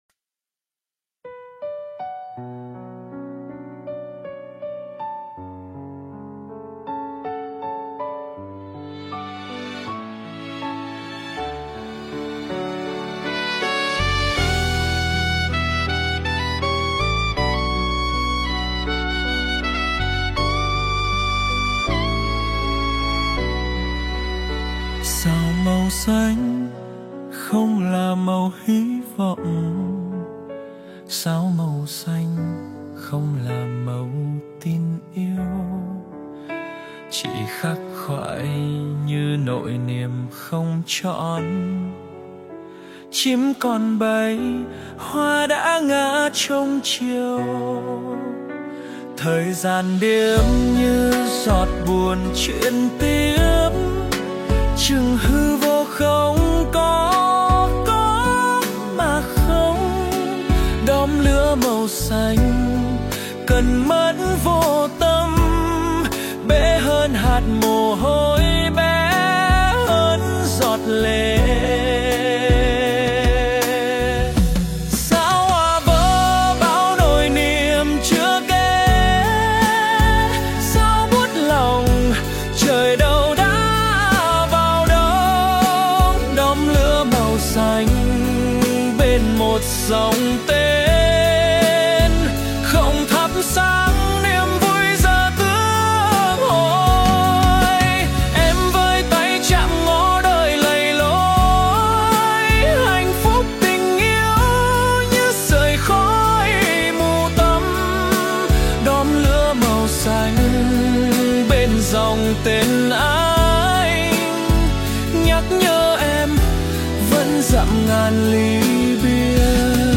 Phổ nhạc: Suno AI